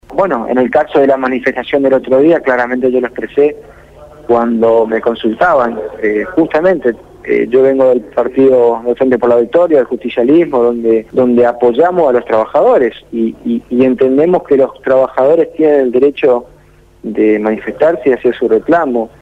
Carlos Espínola, Intendente de la Ciudad de Corrientes habló esta mañana con Radio Gráfica FM 89.3